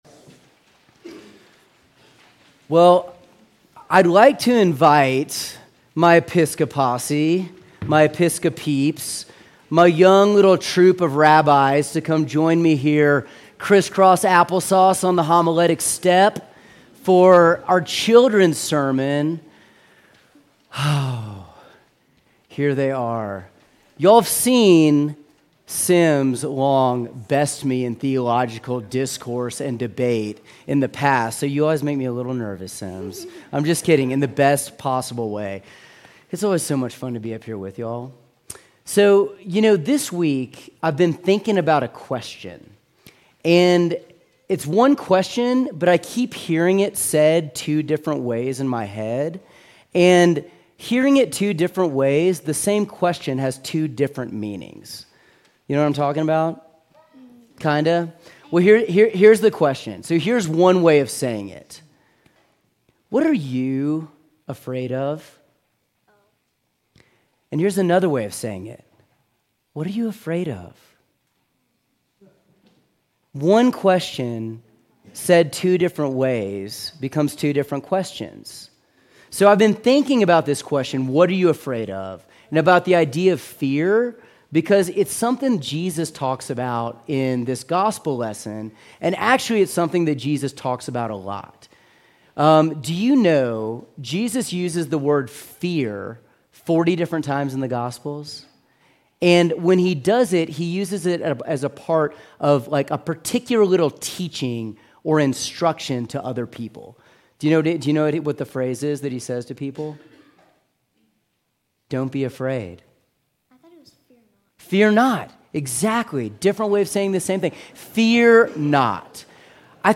Sermons from St. John's Episcopal Church Eighth Sunday after Pentecost